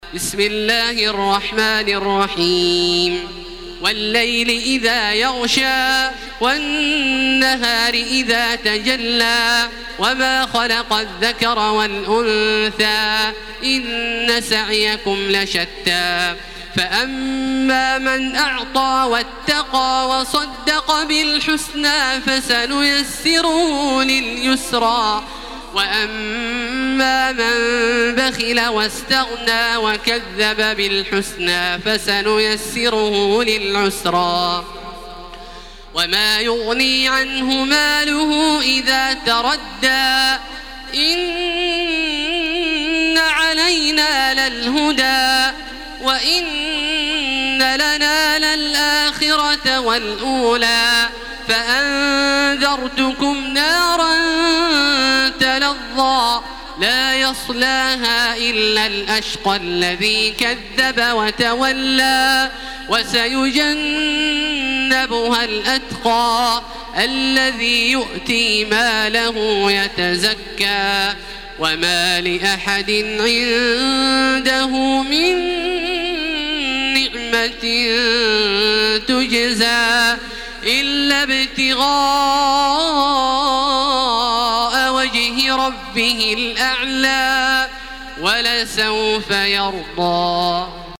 Makkah Taraweeh 1433
Murattal